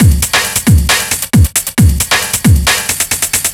Power Break 1 135.wav